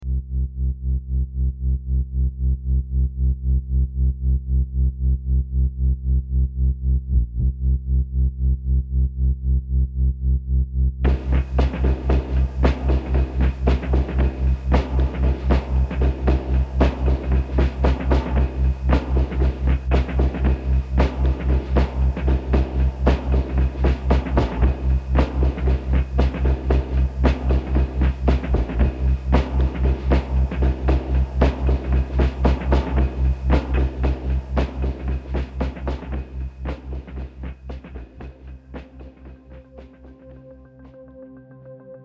Genre : Bandes originales de films